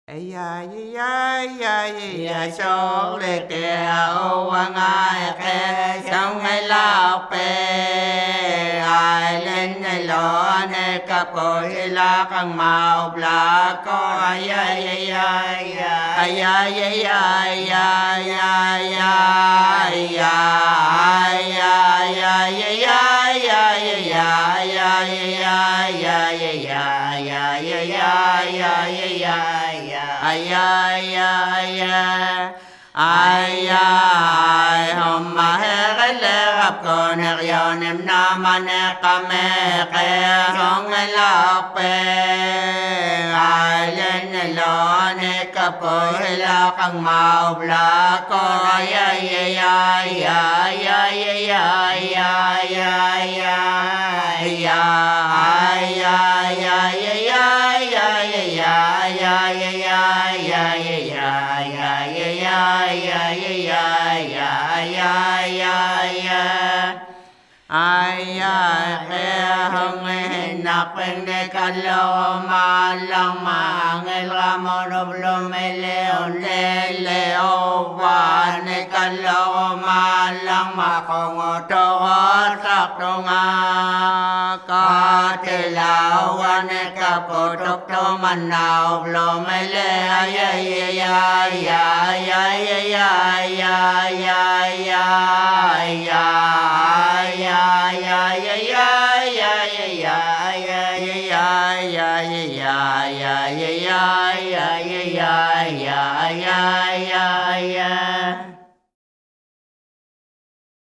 Шаманские песнопения в технике северного горлового пения.
Никаких обработок, никаких сэмплов - чистая аутентика!